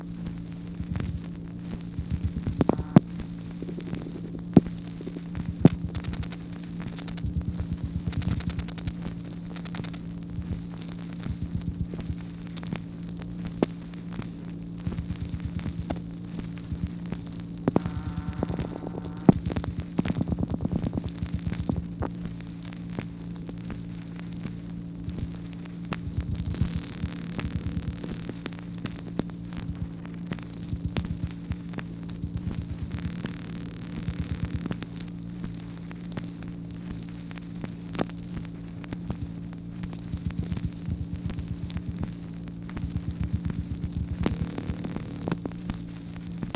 MACHINE NOISE, May 11, 1964
Secret White House Tapes | Lyndon B. Johnson Presidency